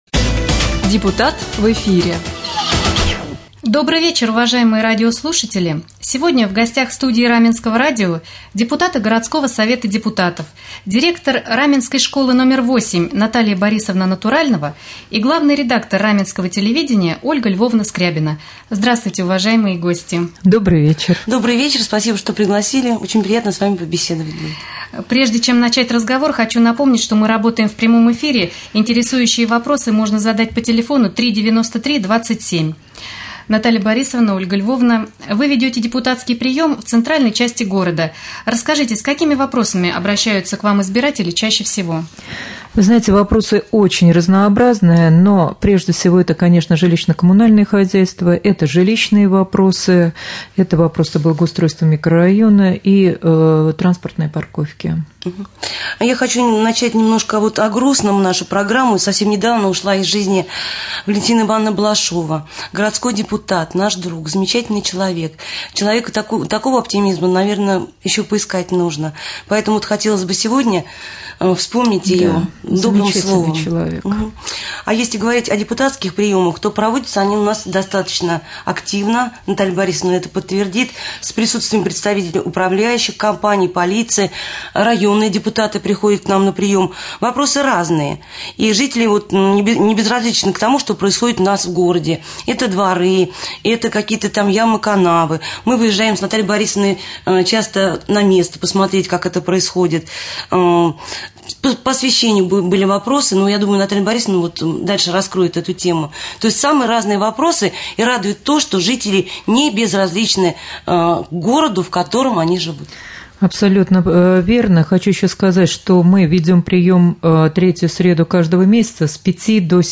Прямой эфир - О.Л. Скрябина и Н.Б. Натуральнова - РамМедиа - Раменский муниципальный округ - Раменское